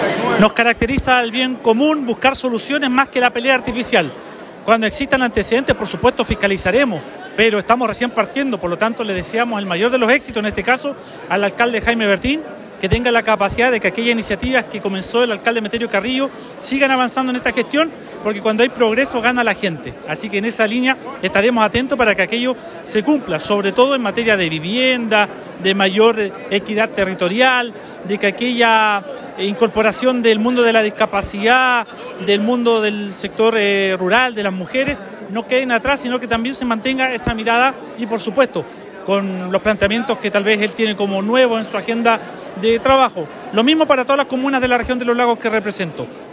El pasado viernes, diversas autoridades nacionales y regionales participaron en la ceremonia de instalación del nuevo gobierno comunal de Osorno, encabezado por el Alcalde Jaime Bertín y su Concejo Municipal.
El Diputado Héctor Barría también participó en la ceremonia, donde expresó su disposición a colaborar activamente con la gestión del nuevo gobierno comunal.